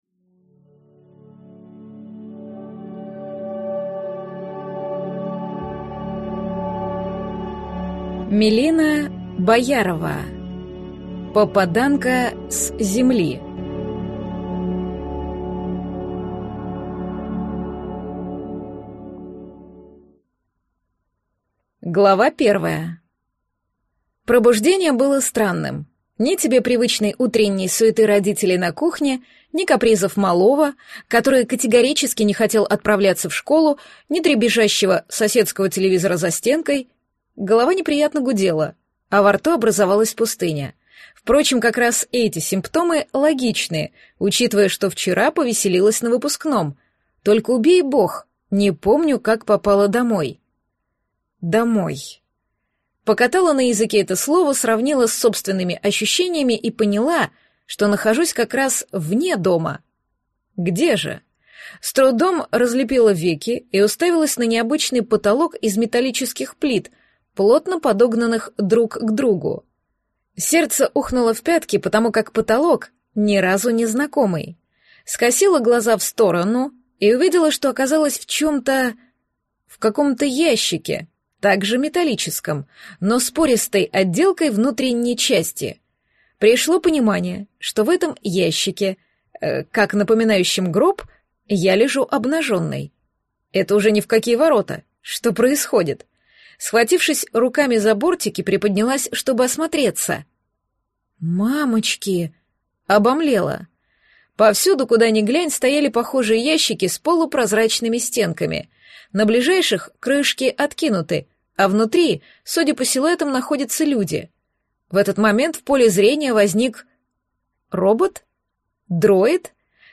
Аудиокнига Попаданка с Земли | Библиотека аудиокниг
Прослушать и бесплатно скачать фрагмент аудиокниги